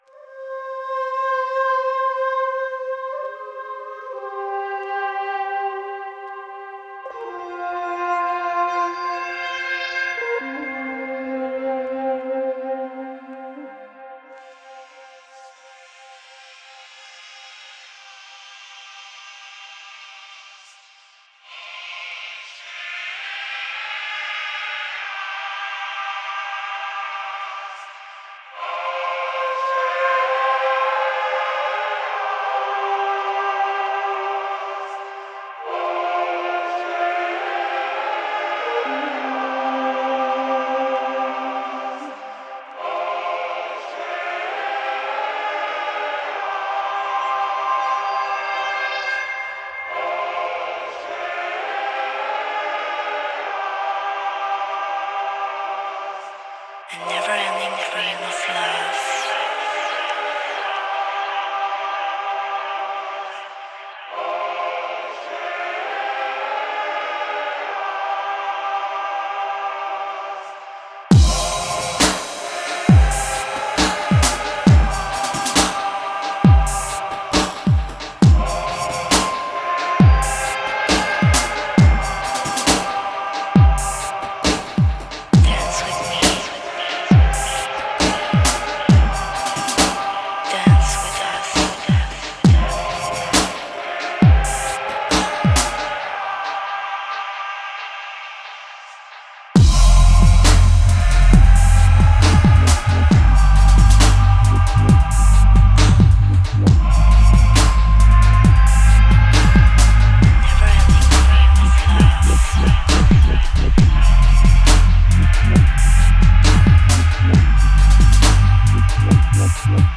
the drums are on point and bass is all there
Cool intro for a set, nice n psycho, sweet bassline!